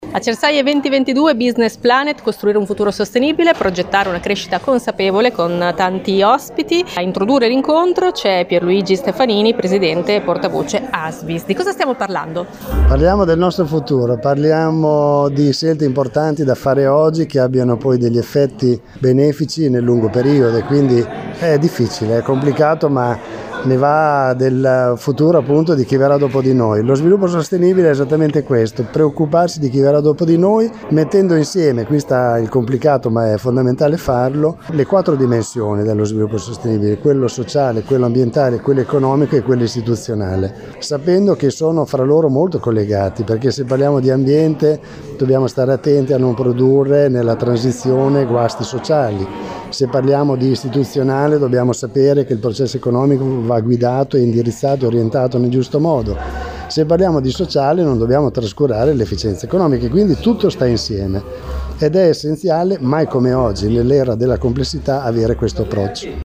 l’intervista a